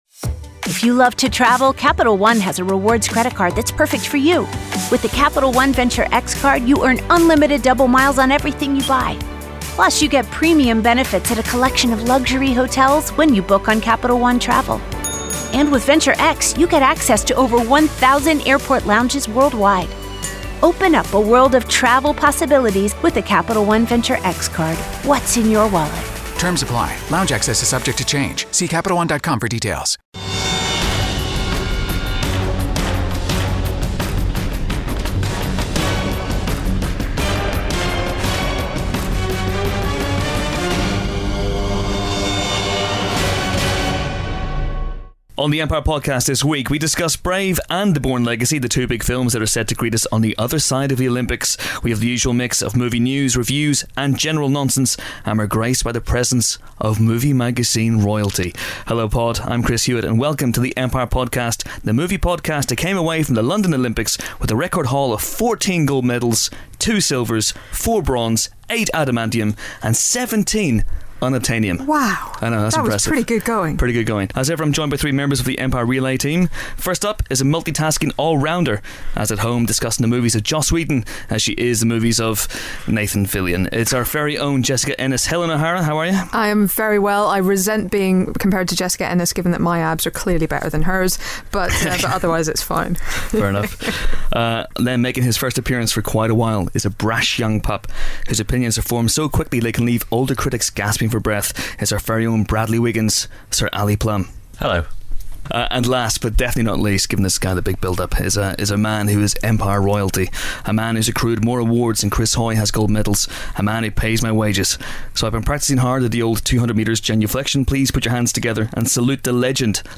So here's one of our rare interview-less efforts, where the team discuss such important things as Joss Whedon returning to direct Avengers 2, the on-going Justice League project as well as reviews for Brave and The Bourne Legacy.